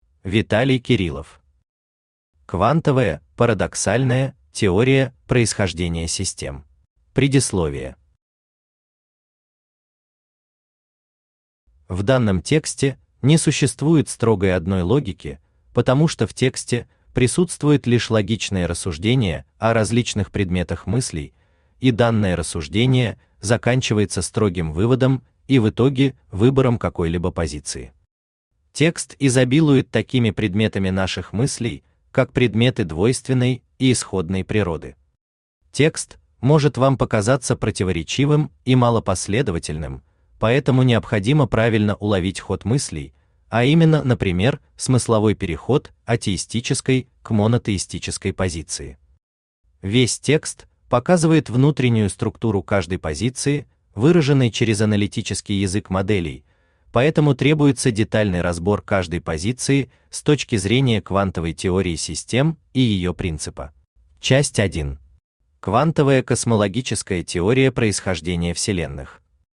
Аудиокнига Квантовая (парадоксальная) теория происхождение систем | Библиотека аудиокниг
Aудиокнига Квантовая (парадоксальная) теория происхождение систем Автор Виталий Александрович Кириллов Читает аудиокнигу Авточтец ЛитРес.